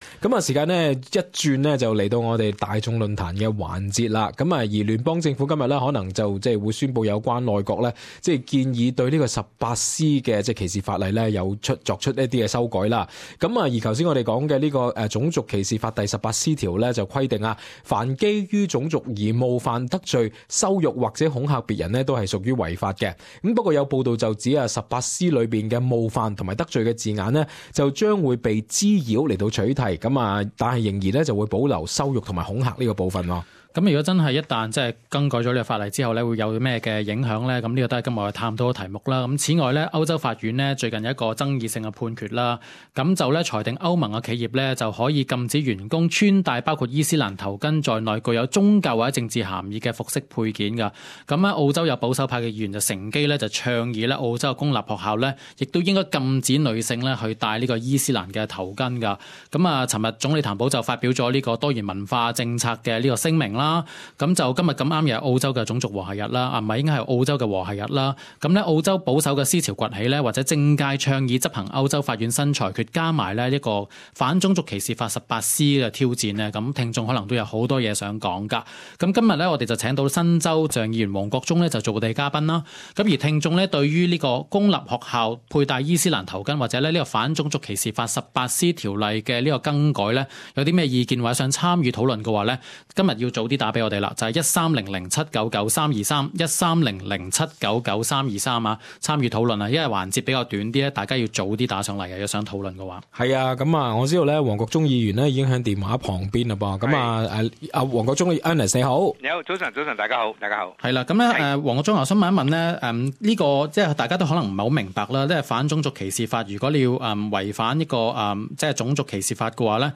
這是澳洲爭議最大的和諧日，兩黨聯盟內閣同意修改《反種族歧視法》18C條款，保守派議員要求澳洲公立學校禁帶伊斯蘭頭巾... 廣東話節目聽眾參與討論。